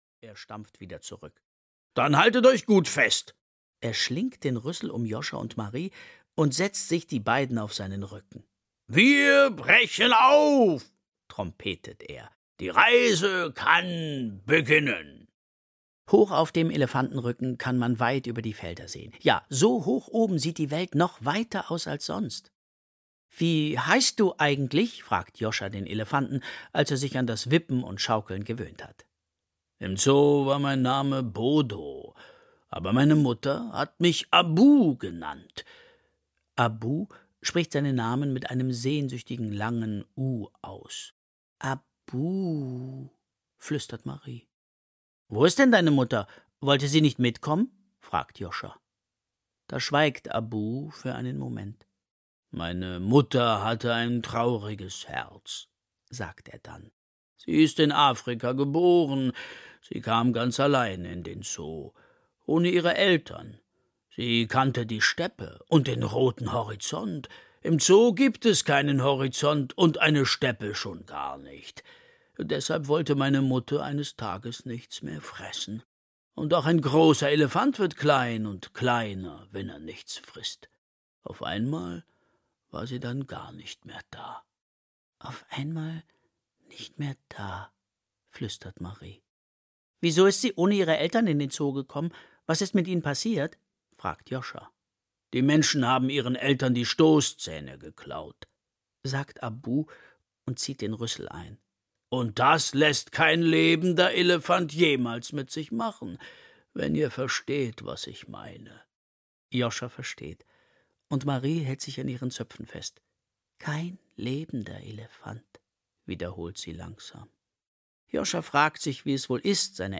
Wir sind nachher wieder da, wir müssen kurz nach Afrika - Oliver Scherz - Hörbuch